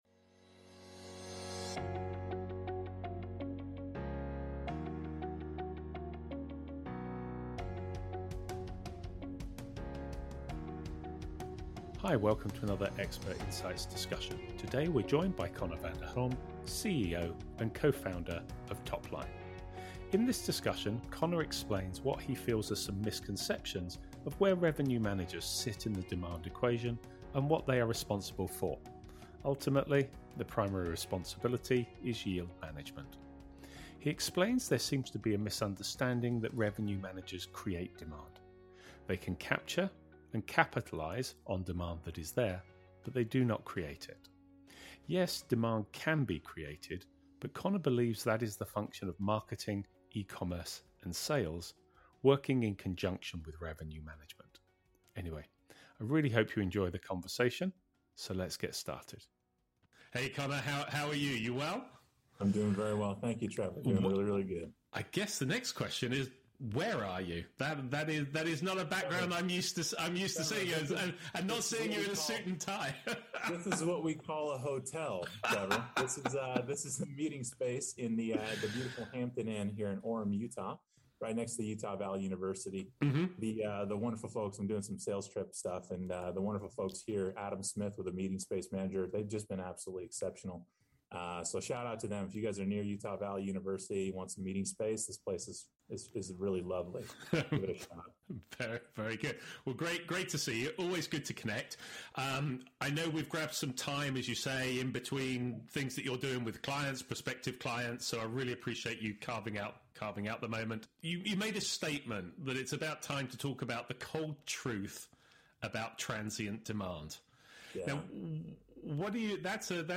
Welcome to another Expert Insights discussion.